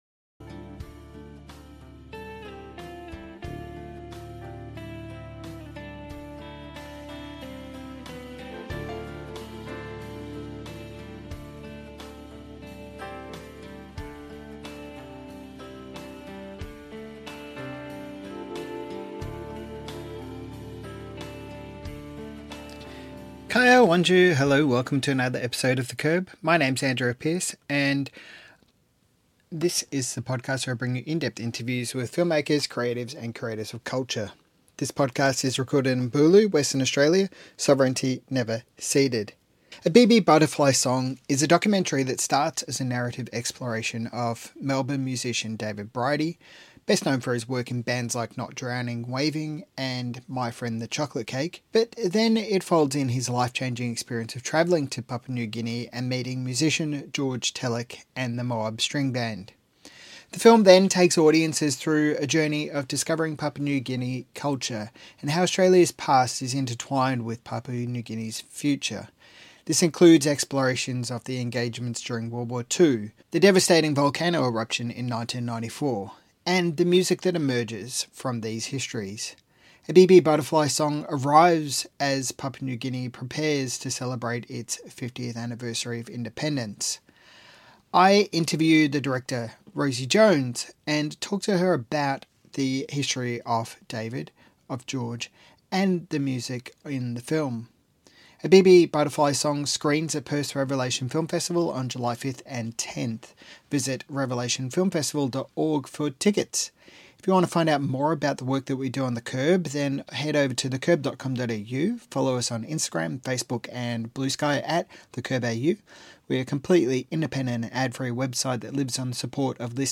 Revelation Film Festival Interview